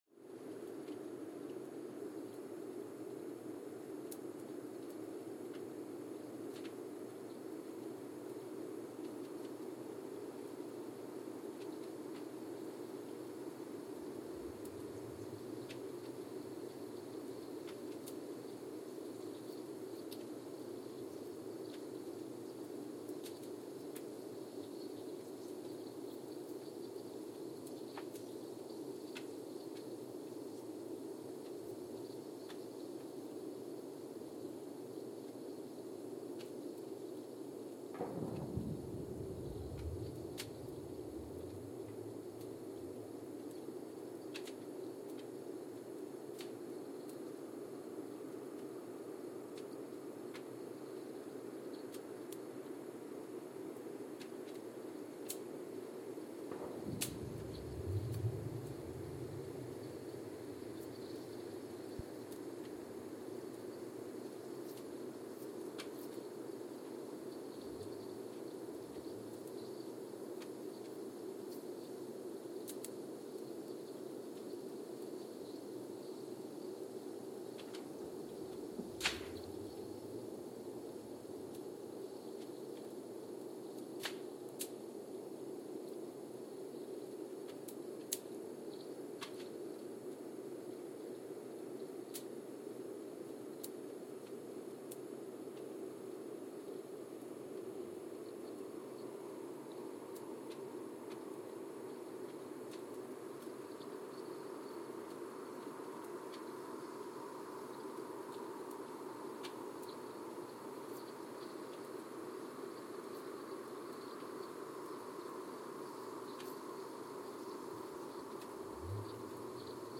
Mbarara, Uganda (seismic) archived on December 20, 2022
No events.
Sensor : Geotech KS54000 triaxial broadband borehole seismometer
Speedup : ×1,800 (transposed up about 11 octaves)
Loop duration (audio) : 05:36 (stereo)